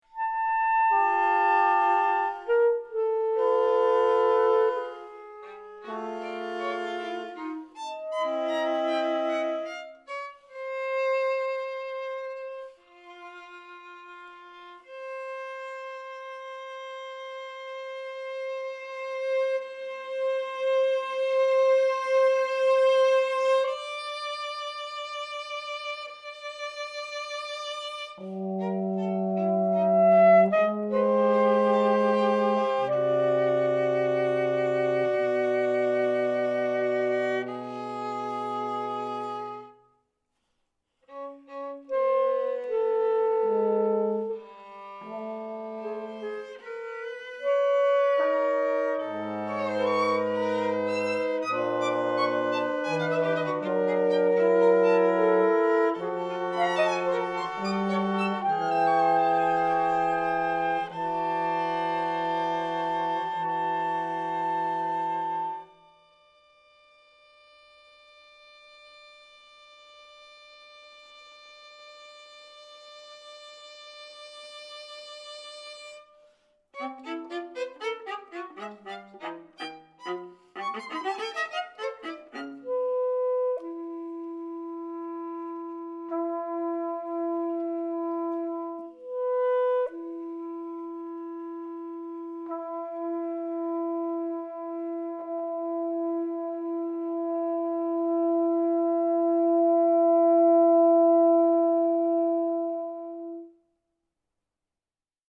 violin
viola
clarinet
trumpet
trombone
electronics